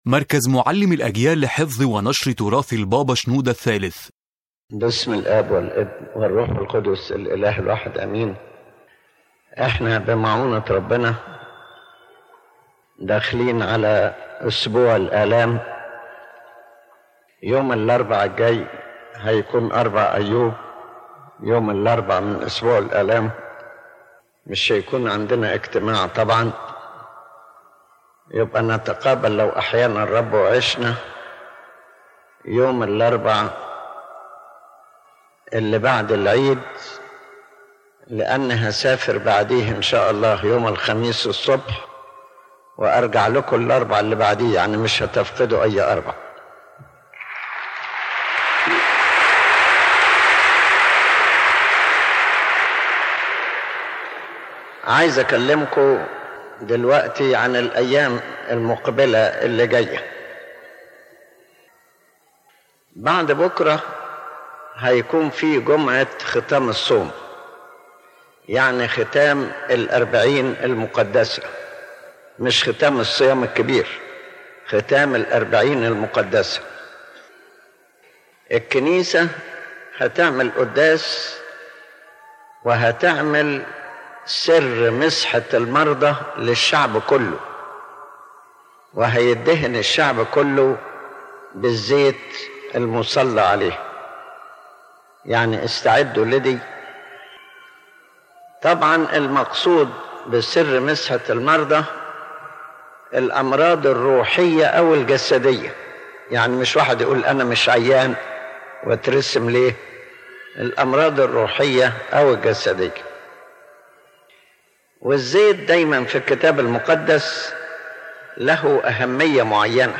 The lecture revolves around the importance of deep spiritual preparation for the Feast of the Resurrection through seriously living the events of Holy Week and benefiting from the church rites as a means of repentance, sanctification, and spiritual growth.